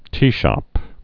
(tēshŏp)